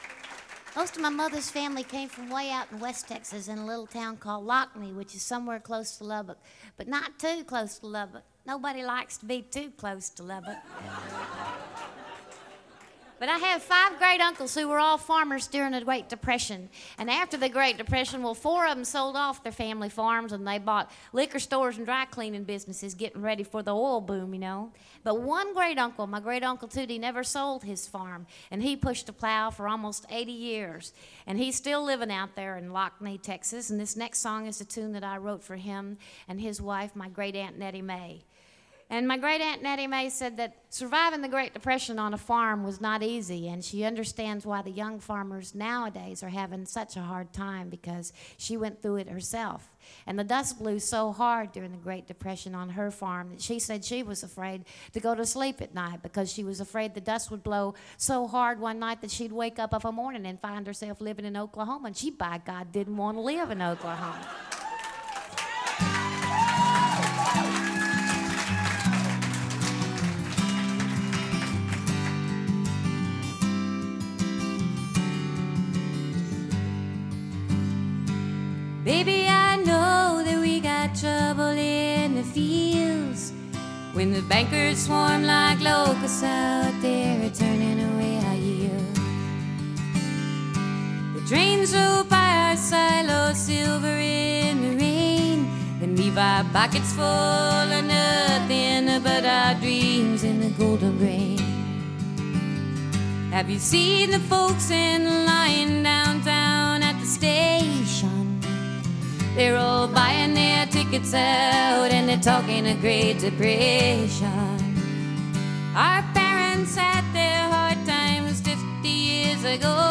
Sweet-voiced songstress
straddled the folk and country genres